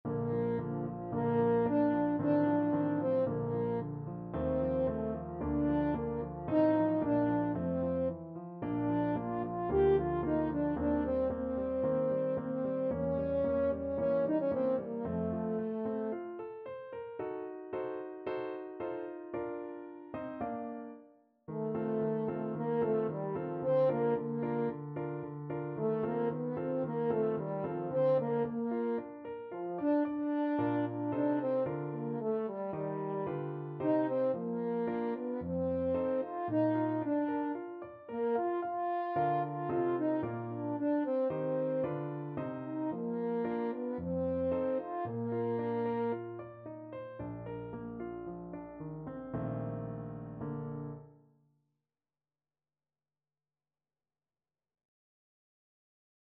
French Horn
Bb major (Sounding Pitch) F major (French Horn in F) (View more Bb major Music for French Horn )
~ = 56 Affettuoso
2/4 (View more 2/4 Music)
F4-G5
Classical (View more Classical French Horn Music)